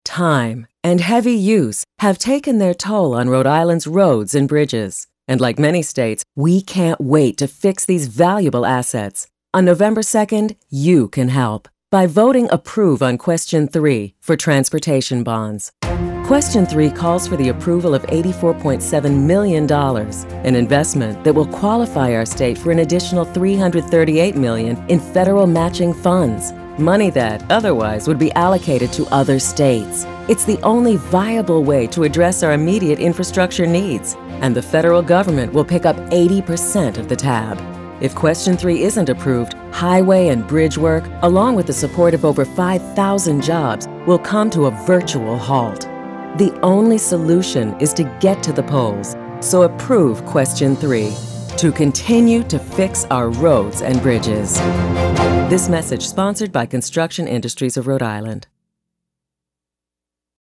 Radio Commercial
An MP3 of the 60-second spot running on RI radio stations.
Approve_Question3_Radio.mp3